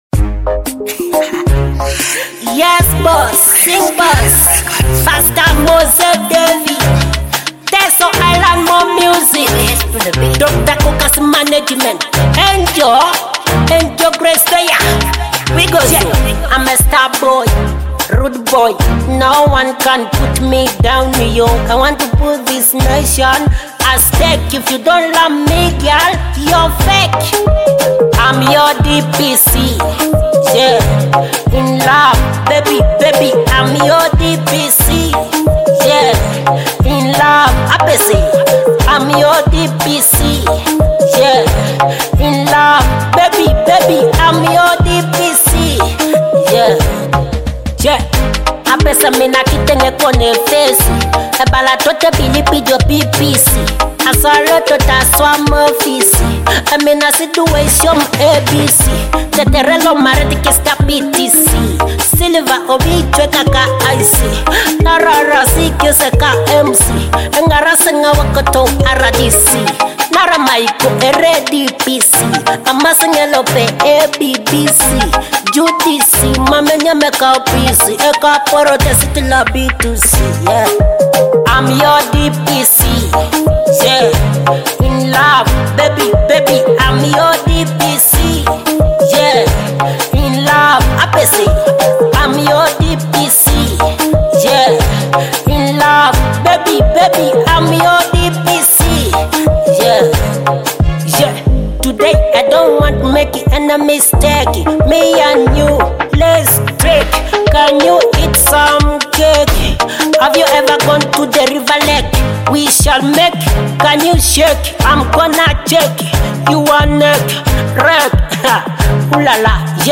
a bold and energetic track